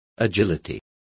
Complete with pronunciation of the translation of agility.